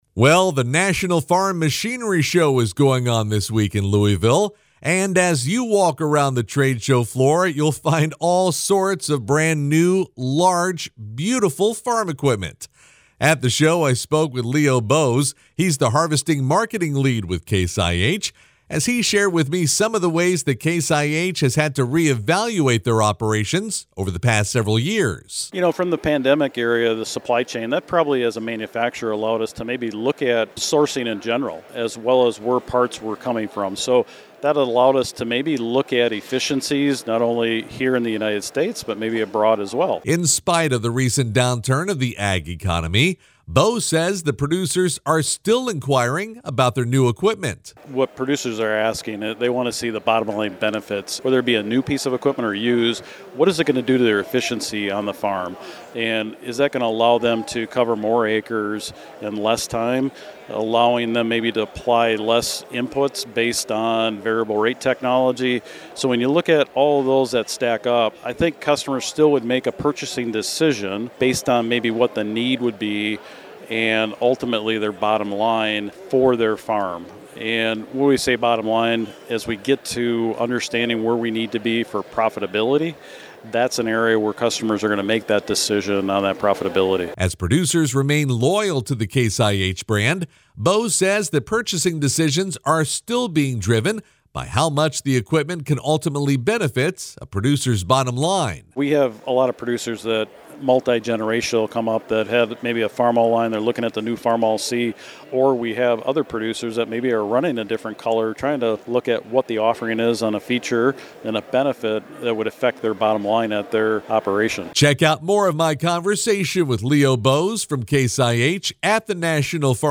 The National Farm Machinery Show is going on in Louisville this week.